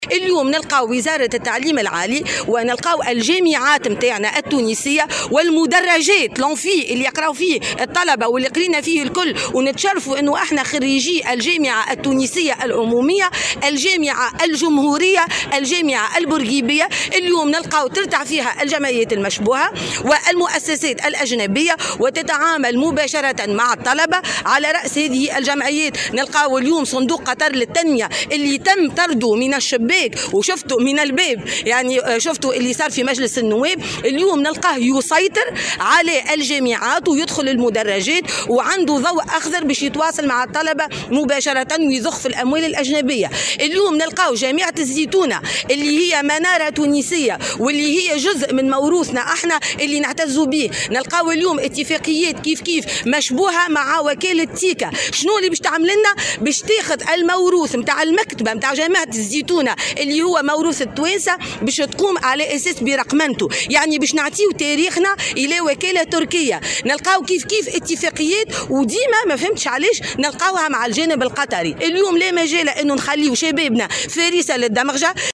Le Parti Destourien Libre (PDL) a tenu, le 25 décembre 2021, une manifestation devant le ministère de l’Enseignement supérieur et de la Recherche scientifique. À cette occasion, la présidente du parti Abir Moussi a souligné la dangerosité des accords de partenariat entes les universités et plusieurs associations et organisations suspectes.